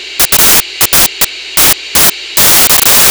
Both turned fully counterclockwise and just quiet stationary steam can be heard.
Stationary Steam Just   Hiss